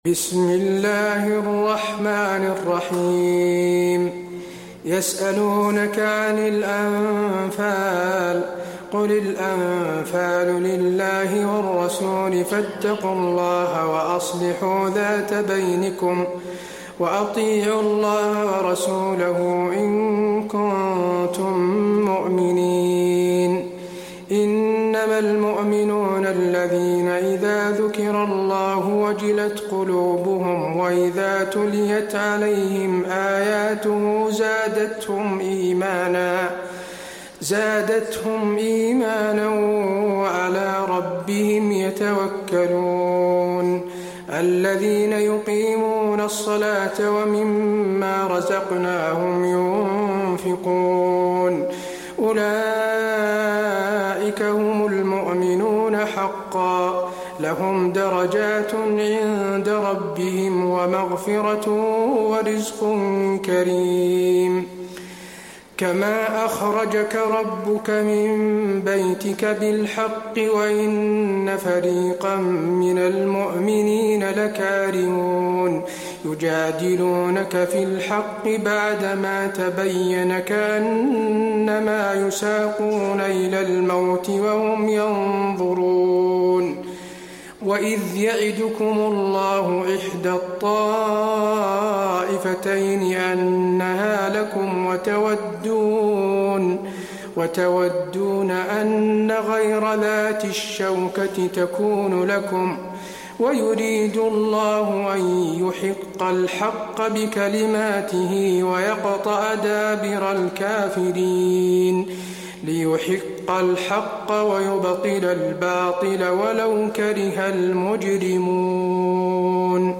المكان: المسجد النبوي الأنفال